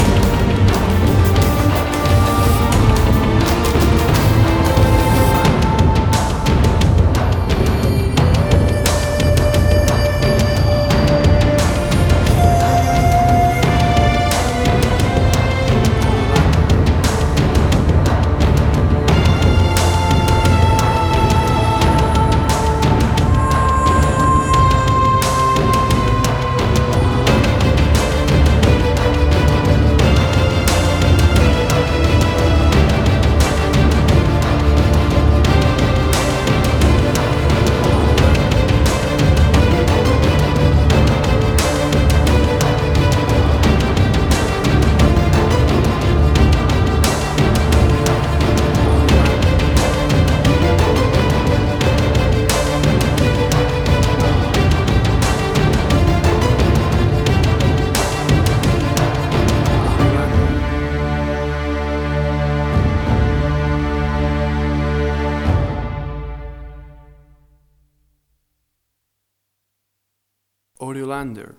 Tempo (BPM): 88